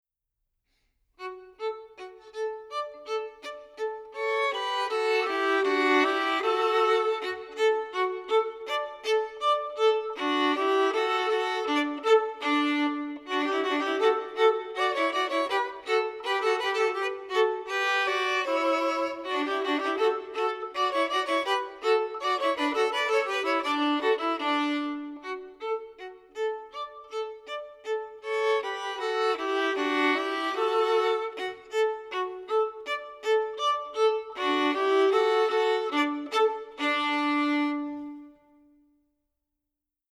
Voicing: Violin Duet